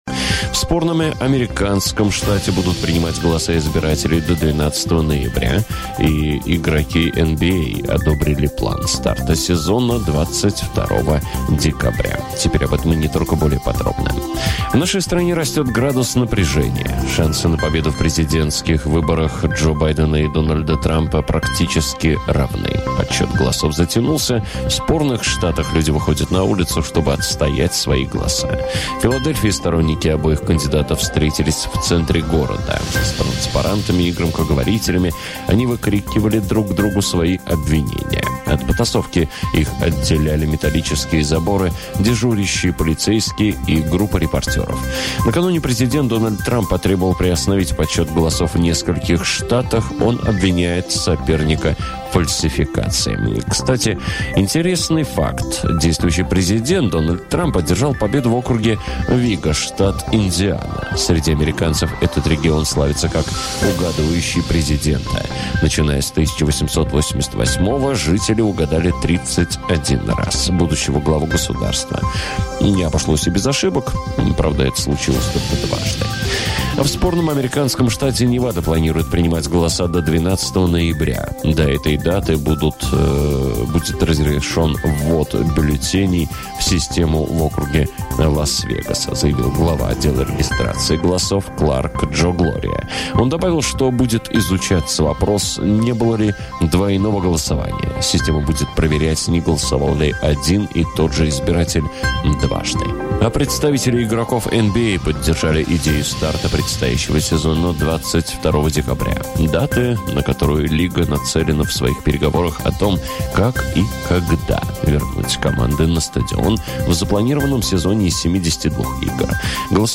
Самое развязное мужское шоу!